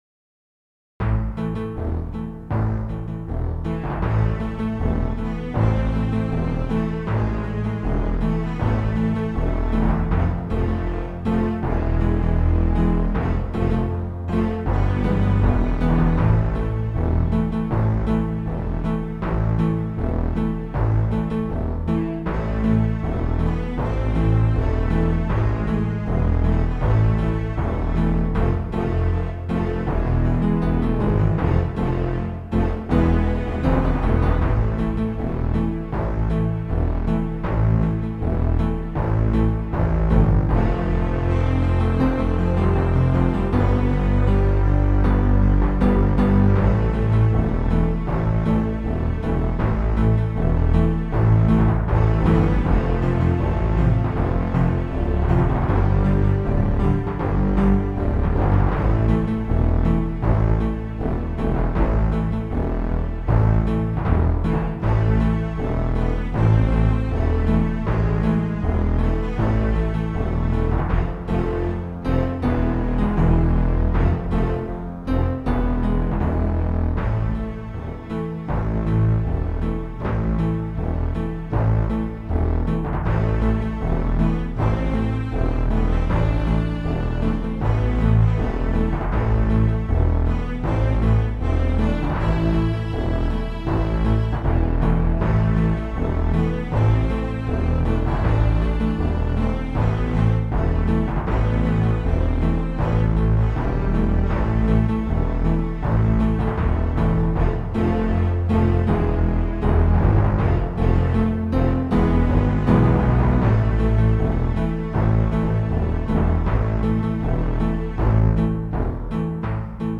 gothic, folk and rock
Recorded digitally, using a Sound Blaster Audigy 2 SE.